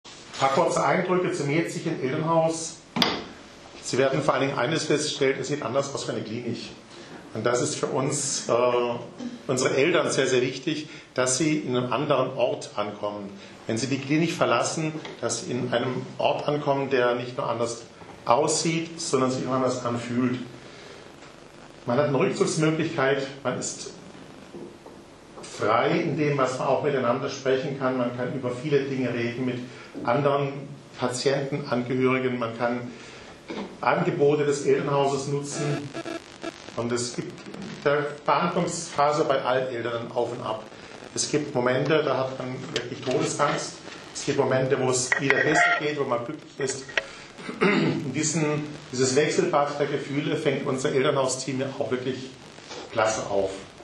Pressekonferenz "Neubau Elternhaus"